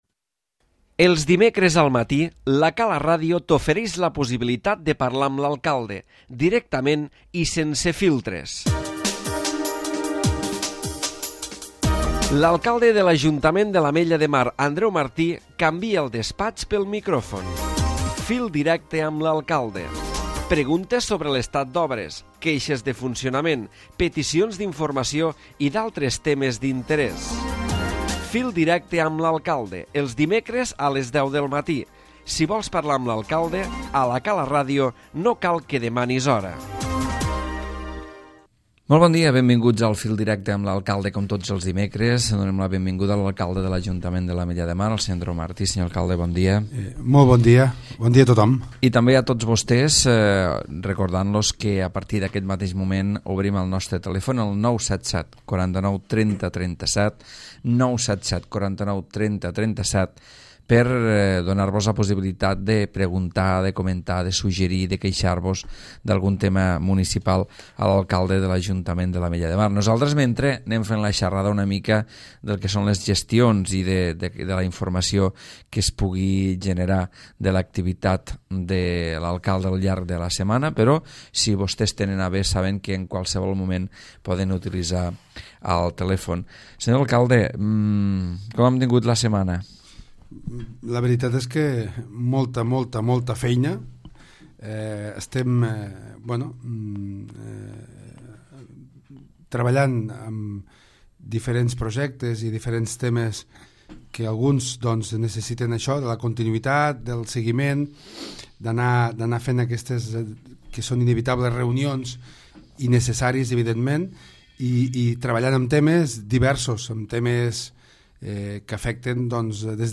Andreu Martí, alcalde de l'Ajuntament de l'Ametlla de Mar ha parlat de la seva gestió al capdavant de l'ajuntament i com cada dimecres ha atès les trucades dels oients.